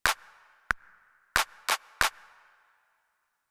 Here you will find rhythms with various types of notes in the bar.
One half note, two eighth notes, and one quarter note.